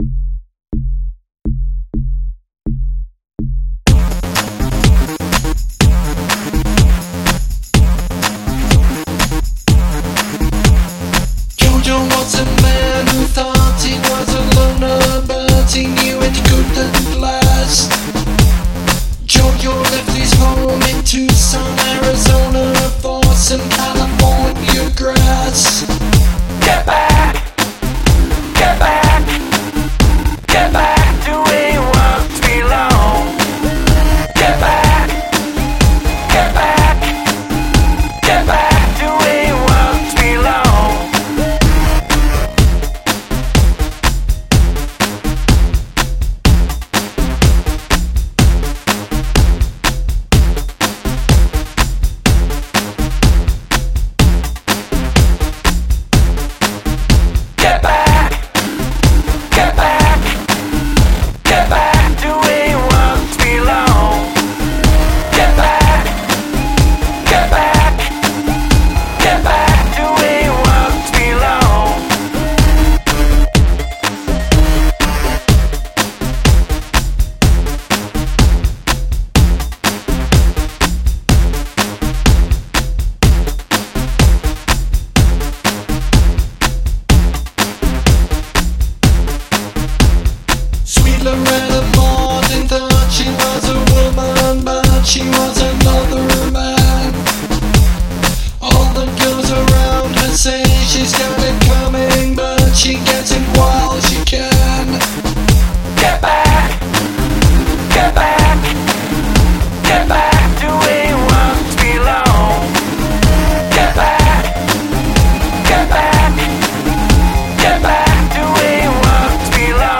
ukulele